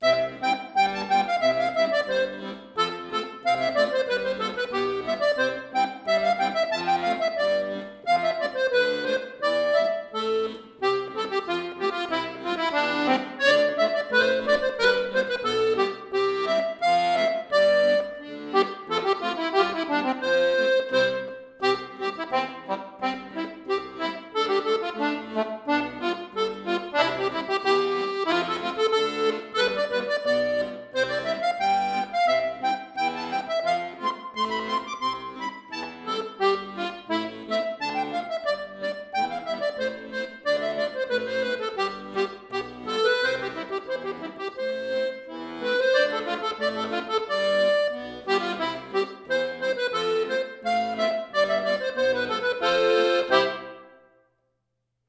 Folksong , Irish